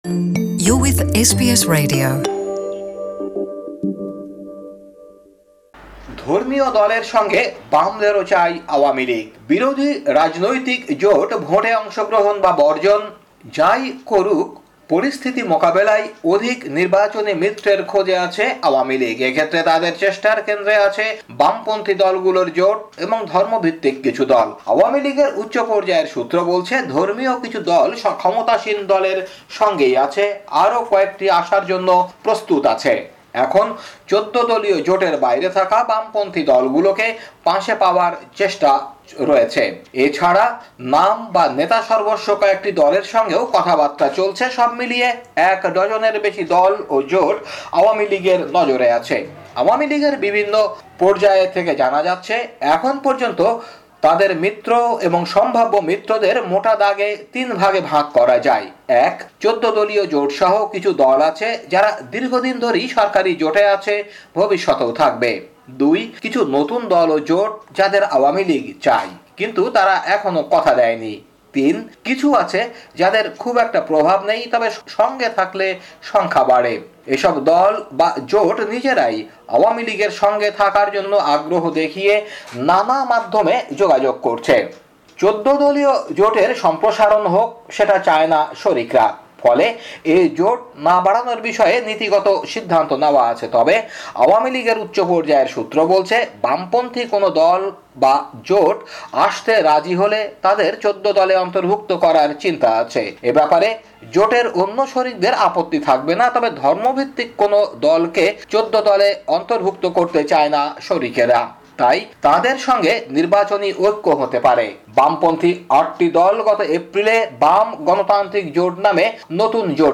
বাংলাদেশী সংবাদ বিশ্লেষণ: ২৬ অক্টোবর ২০১৮